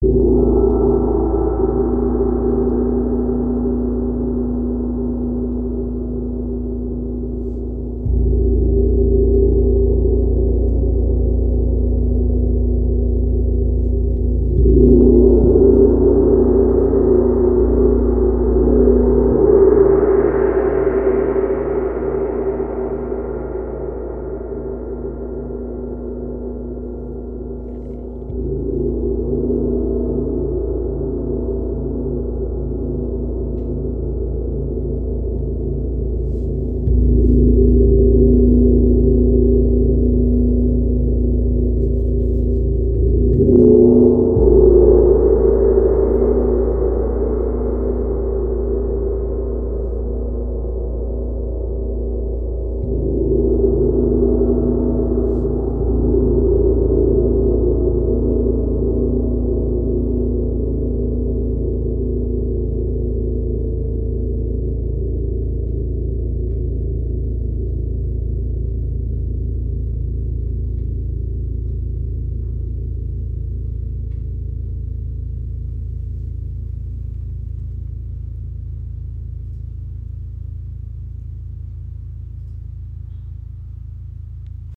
Tempel Gong | Grüne Tara | 90 cm im Raven-Spirit WebShop • Raven Spirit
Klangbeispiel
Dieser ganz besondere Tempel Gong hat wie der Chao oder Tam Tam Gong einen gehämmerten Rand und wurde in Nepal im Kathmandu-Tal erschaffen.